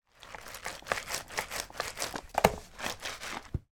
Звуки краски
Валик плавно катится в краску в пластиковом лотке бережно